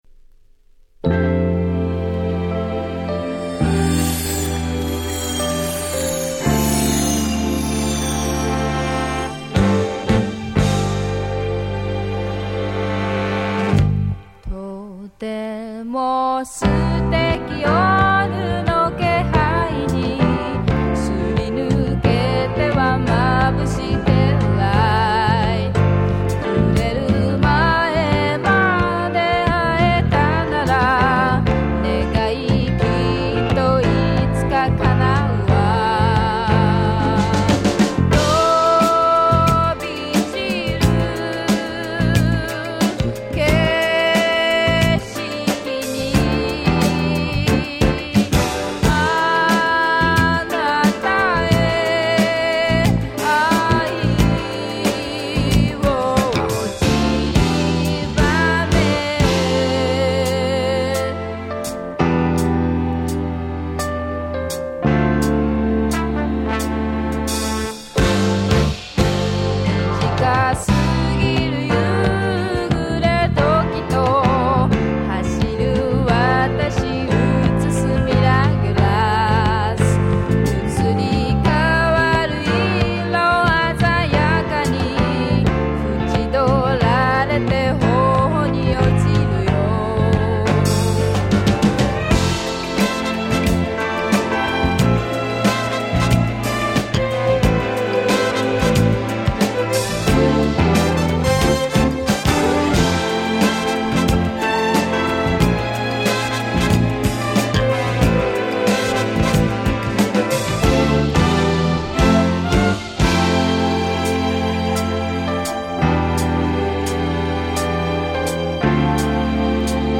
試聴ファイルは別の盤から録音してあります。